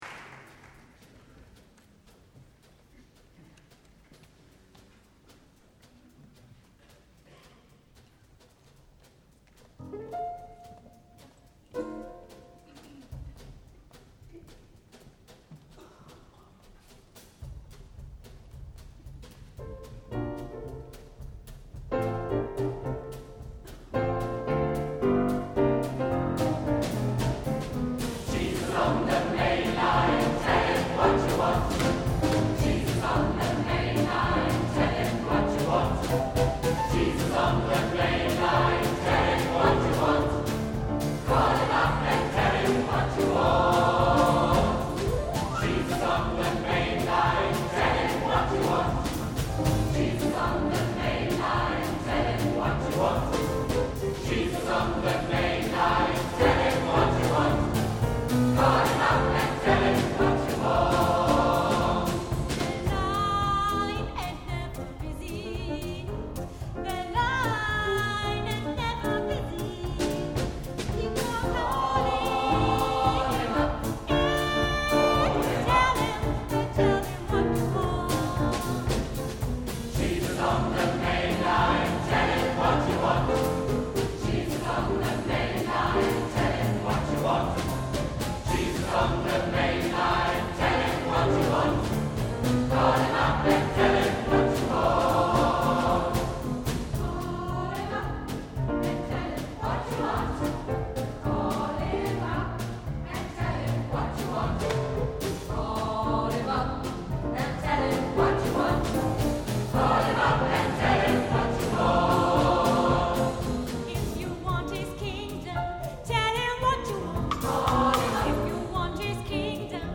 Gospelchor - Evangelische Kirche Bad Neuenahr
Das Repertoire umfasst neben klassischer Gospelmusik auch die Bereiche Jazz und Pop.